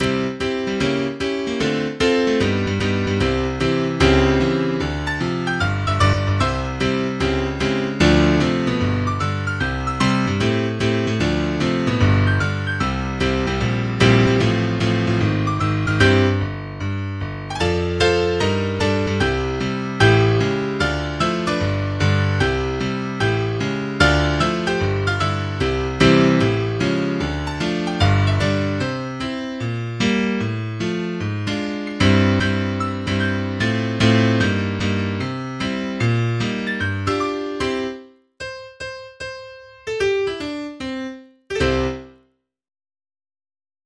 MIDI 3.7 KB MP3
jazzy upbeat swing rhythm